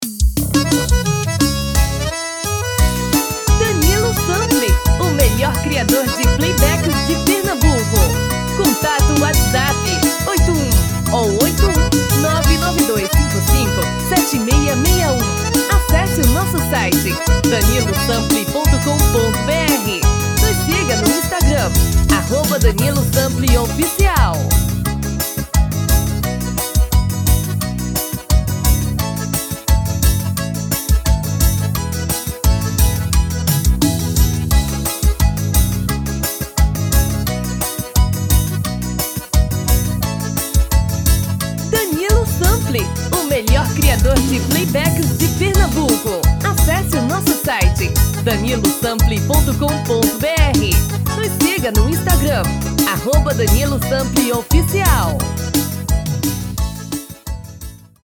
DEMO 1: tom original DEMO 2: tom masculino